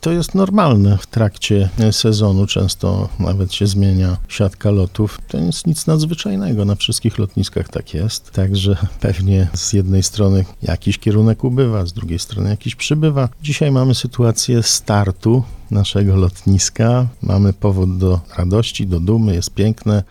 Czym są jednak spowodowane takie zmiany i czy zagrażają powodzeniu tego lotniczego przedsięwzięcia? Na to pytanie odpowiedział podczas Mocnej Rozmowy na antenie Radia Radom poseł PiS Marek Suski: